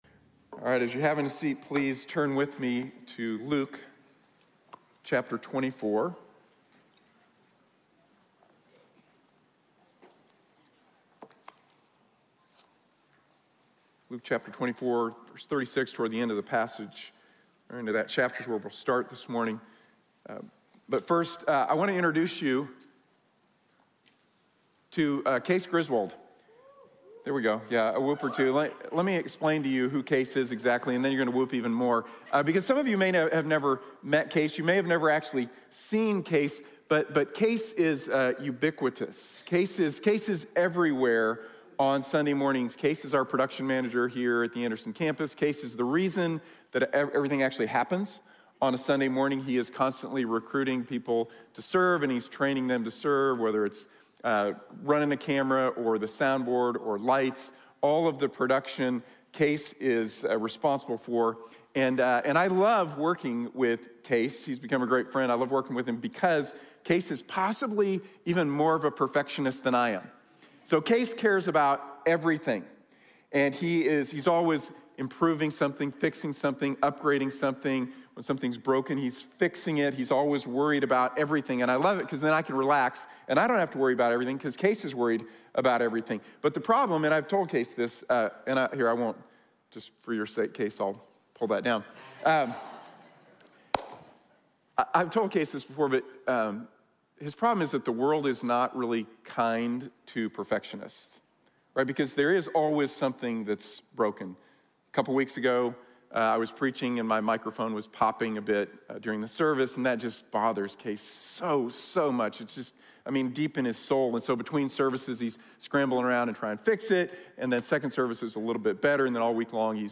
| Sermon | Grace Bible Church